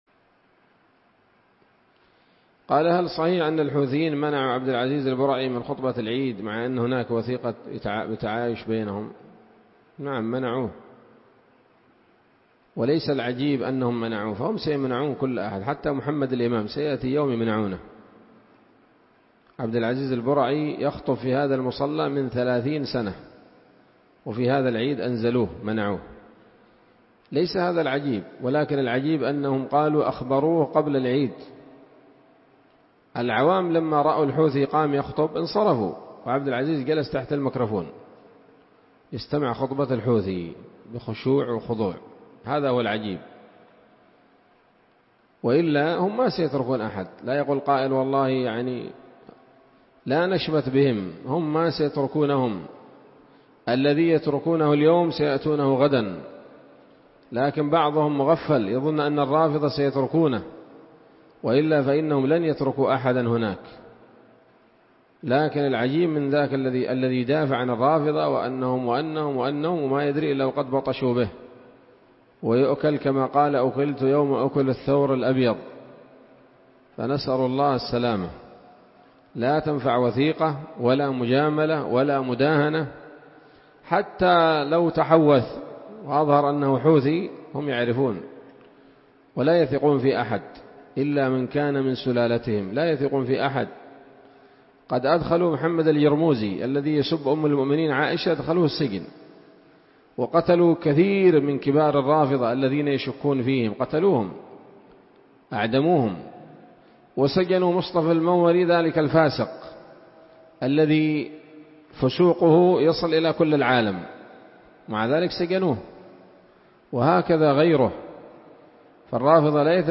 عصر الإثنين 2 شوال 1446 هـ، دار الحديث السلفية بصلاح الدين